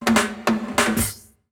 British SKA REGGAE FILL - 14.wav